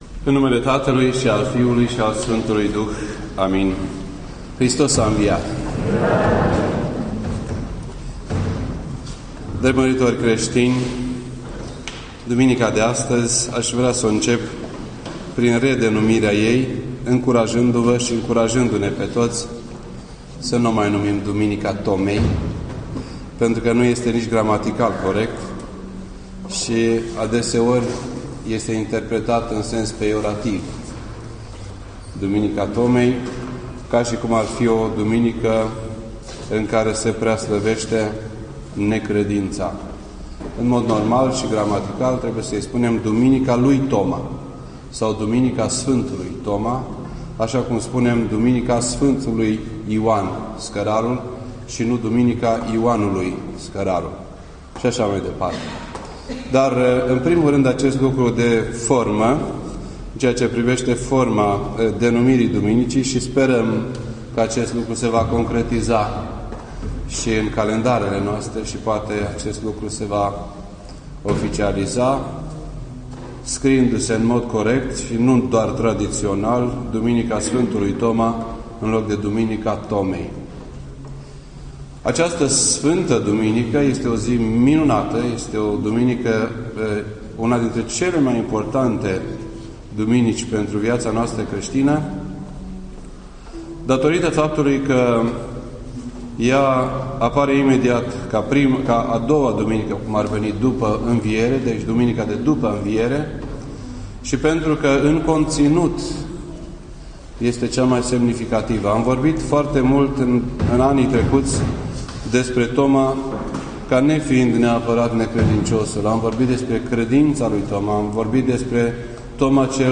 This entry was posted on Sunday, April 22nd, 2012 at 8:49 PM and is filed under Predici ortodoxe in format audio.